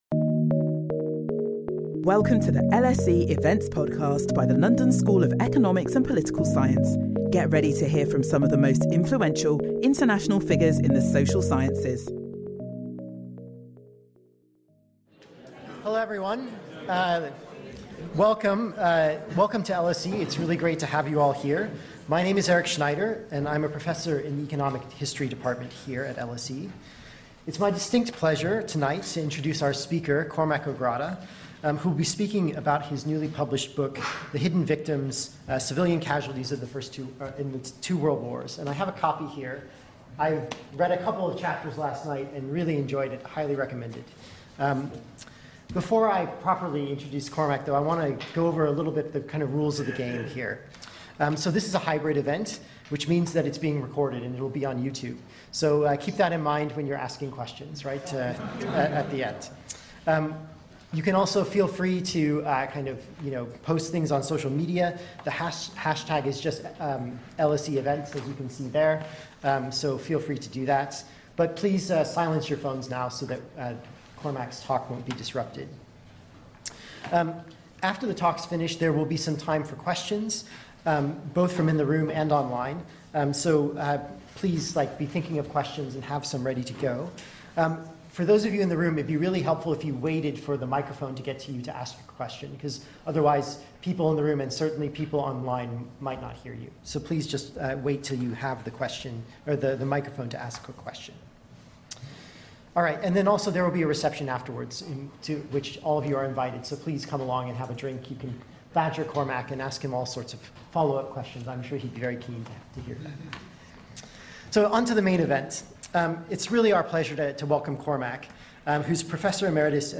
In his latest book, which forms the basis of this lecture, Cormac O'Grada argues that previous estimates of civilian deaths in the two world wars are almost certainly too low.